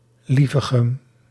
Lievegem (Dutch pronunciation: [ˈlivəˌɣɛm]
Nl-Lievegem.ogg.mp3